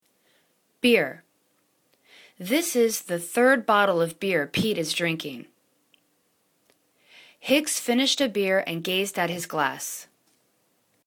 beer    /biәr/    n